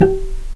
healing-soundscapes/Sound Banks/HSS_OP_Pack/Strings/cello/pizz/vc_pz-G4-pp.AIF at 01ef1558cb71fd5ac0c09b723e26d76a8e1b755c
vc_pz-G4-pp.AIF